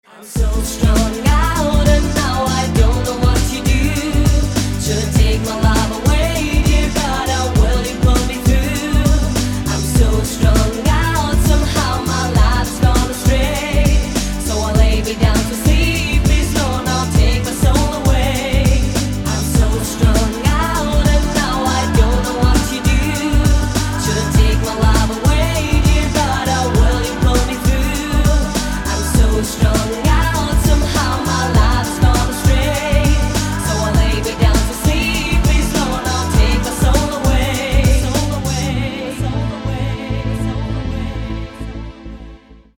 • Качество: 192, Stereo
женский вокал
90-е
vocal